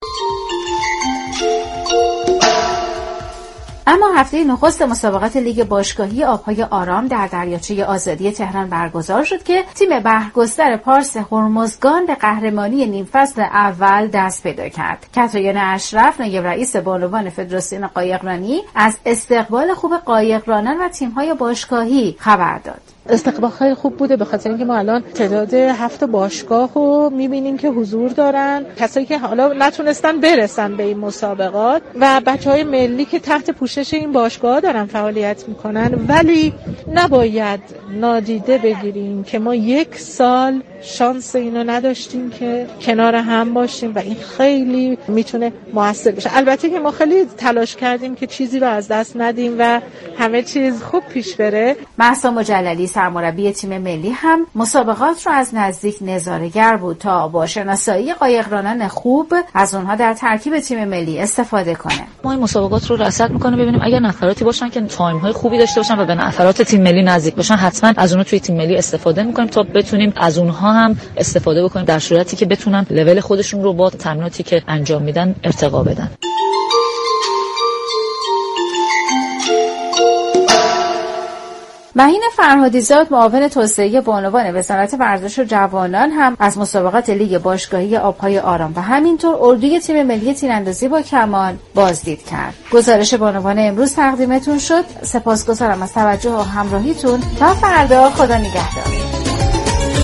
شما می توانید از طریق فایل صوتی پیوست بطور كامل شنونده این گفتگو باشید.